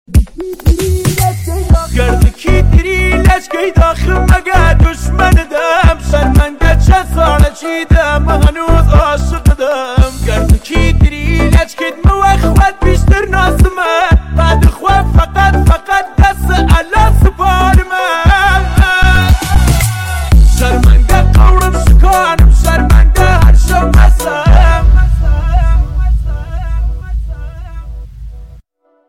( بیس دار تند )